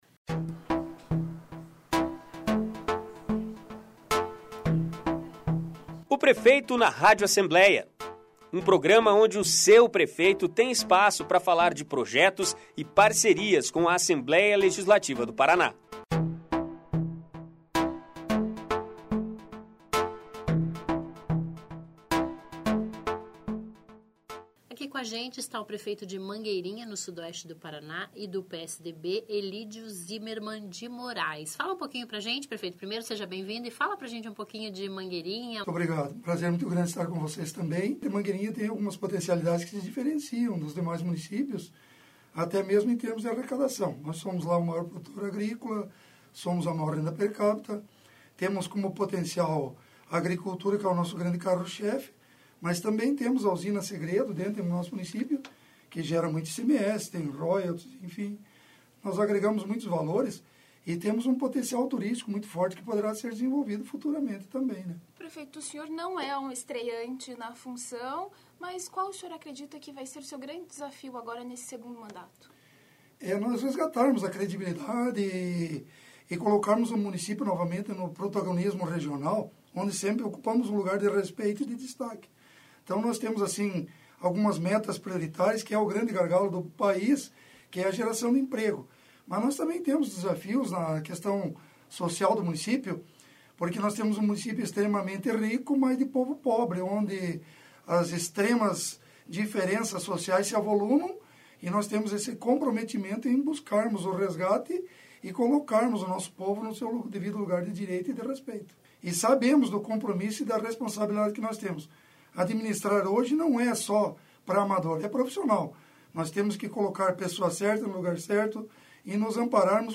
Ouça a íntegra da entrevista com prefeito  Elídio Zimerman, de Mangueirinha. Ele é o convidado desta semana do programa  "Prefeito na Rádio Alep".